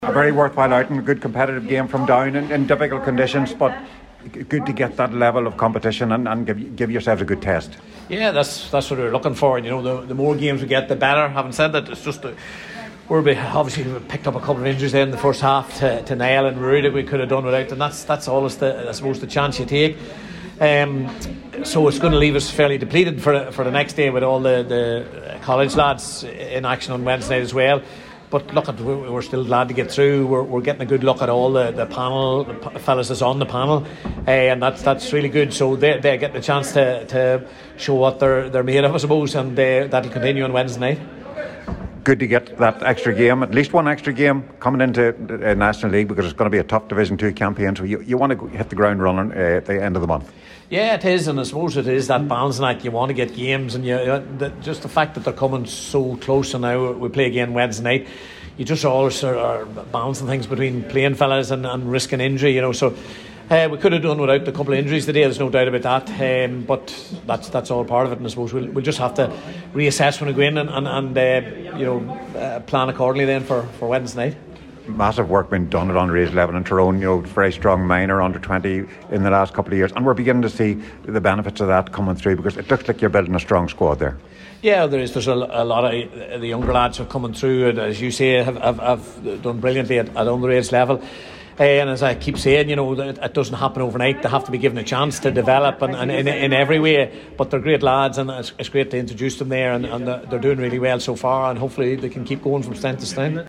at full time after booking their spot in the semi-finals this coming Wednesday evening…